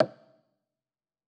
Wood Block2.wav